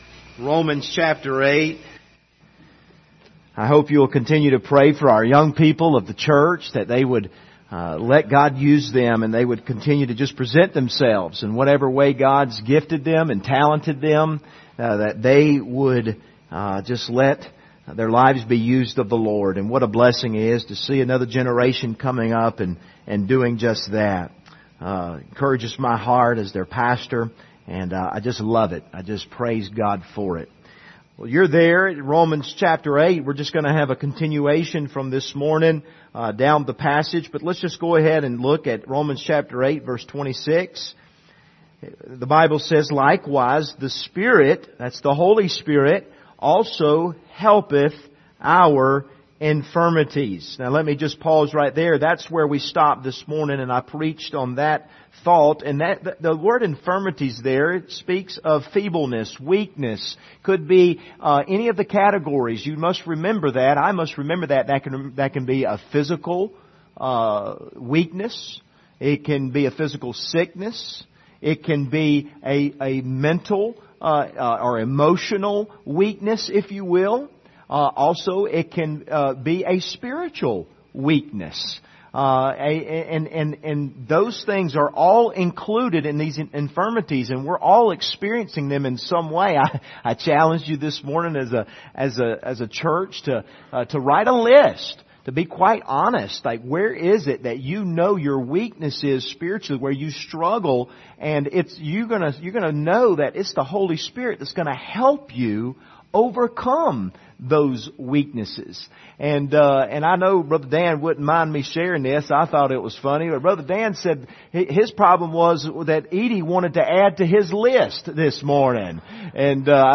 Passage: Romans 8:26-31 Service Type: Sunday Evening